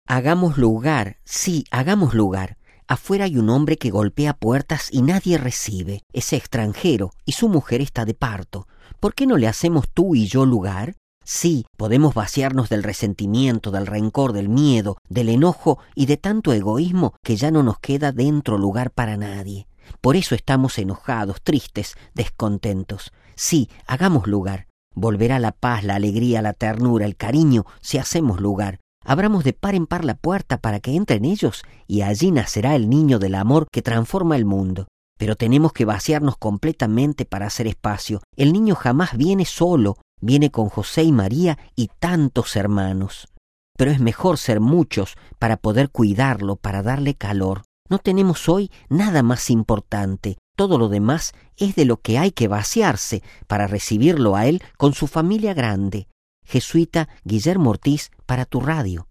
(RV).- (Audio con música) RealAudioMP3 (Sin música) RealAudio